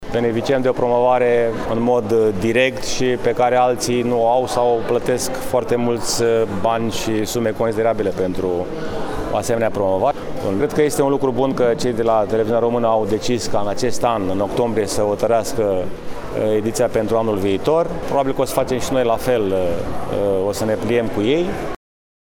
Și primarul Brașovului, George Scripcaru, dă asigurări că pregătirile pentru ediția de anul viitor vor începe chiar din această toamnă: